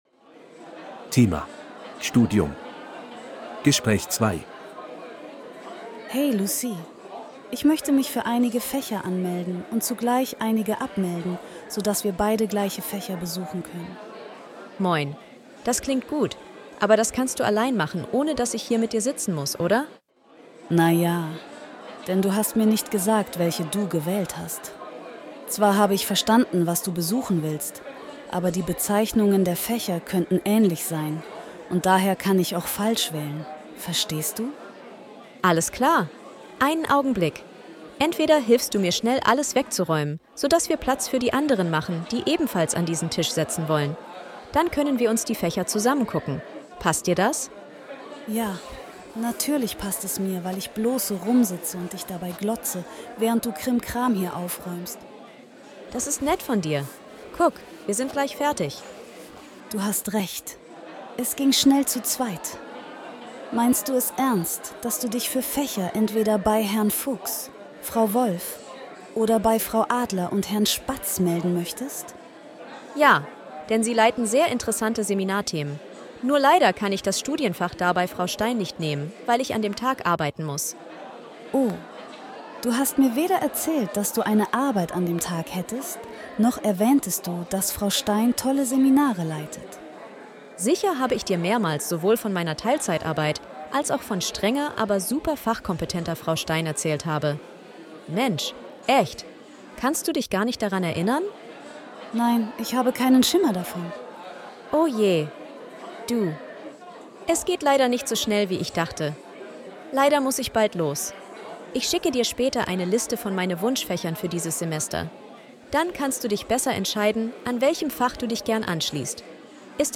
Bài nghe hội thoại 2:
B1-Registeruebung-5-Studium-Gespraech-2.mp3